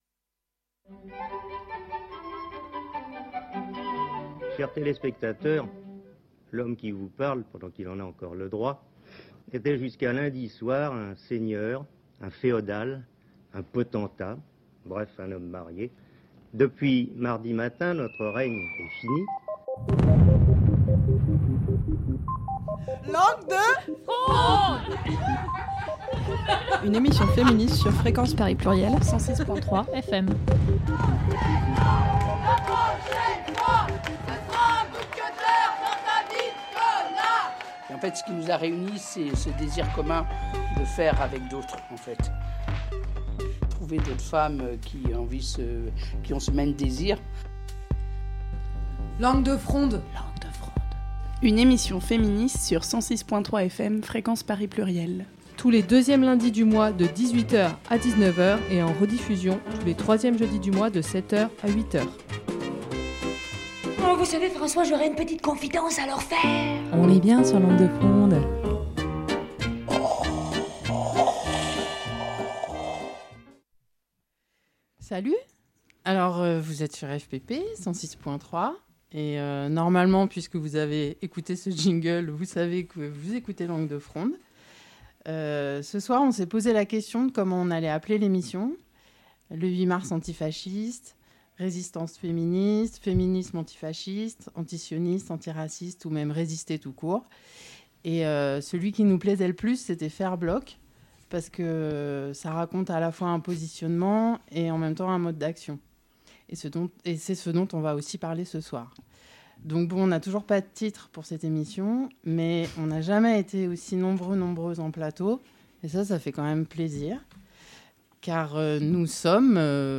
Mais celui qui nous plaisait le plus, avec nos invité·es du CLAF (Collectif en Lutte pour l’Autodetermination Féministe) du BAD (Bloc d’Action Déviant·e) des Féministes Révolutionnaires en plateau et d’Urgence Palestine en enregistré, c’était Faire Bloc . C’est ce qu’on a fait avec elleux, pour raconter cette manif et ses stratégies, célébrer les alliances féministes et leur radicalité.